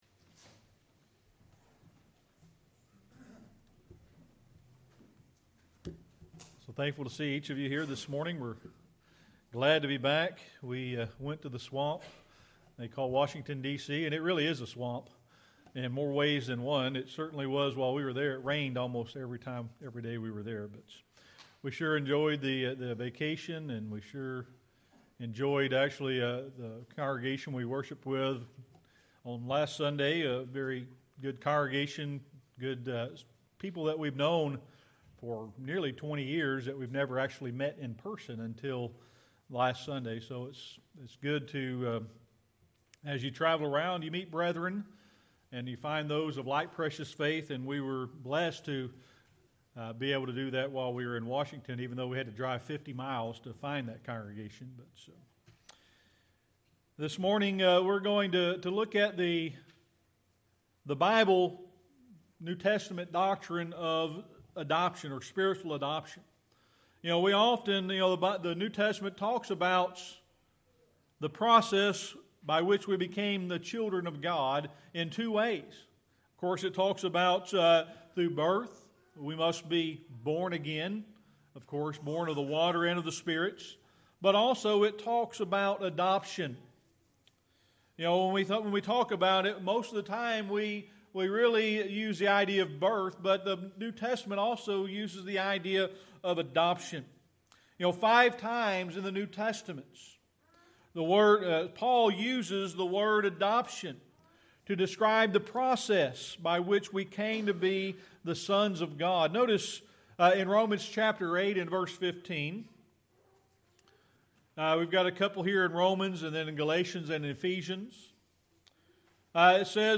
Ephesians 1:3-11 Service Type: Sunday Morning Worship The process by which we become children of God is shown by two ways in the New Testament.